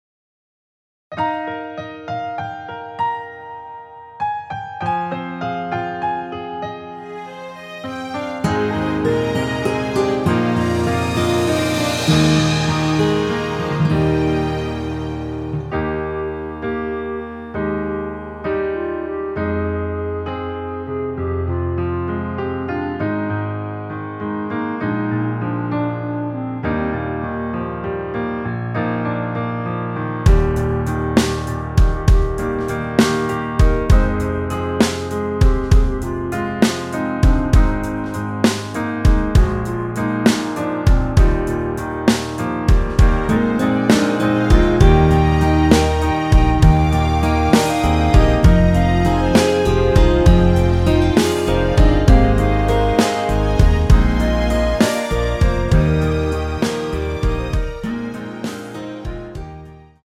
원키에서(-5)내린 멜로디 포함된 짧은 편곡 MR입니다.
Eb
앞부분30초, 뒷부분30초씩 편집해서 올려 드리고 있습니다.
중간에 음이 끈어지고 다시 나오는 이유는